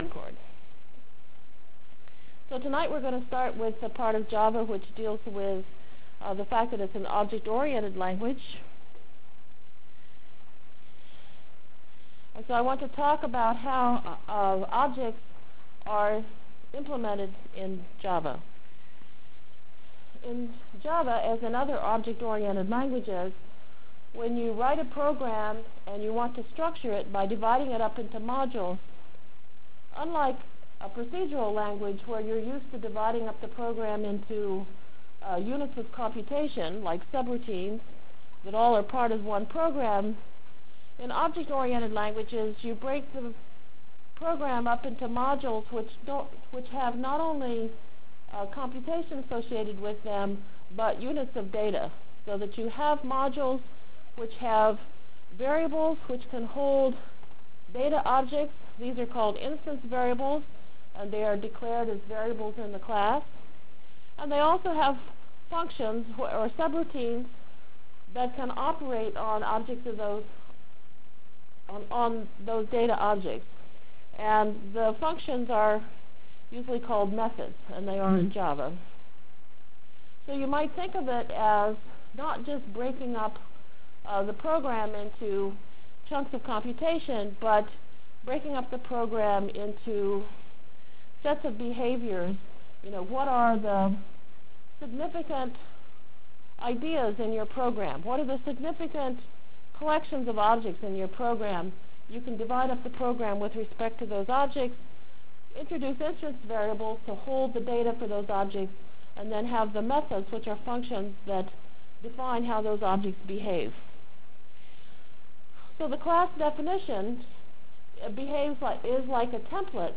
From Jan 27 Delivered Lecture for Course CPS616 -- Java Lecture 2 -- Basic Applets and Objects CPS616 spring 1997 -- Jan 27 1997.